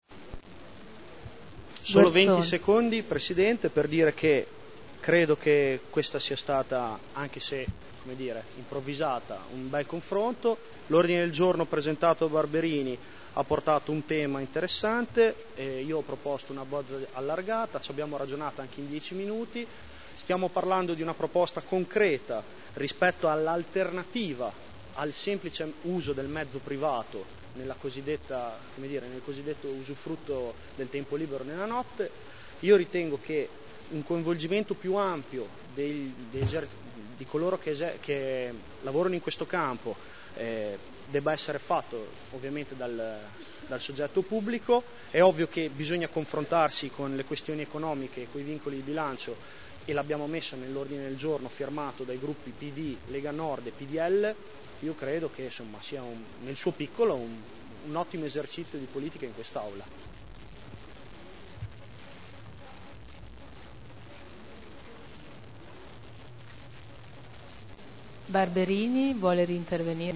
Giulio Guerzoni — Sito Audio Consiglio Comunale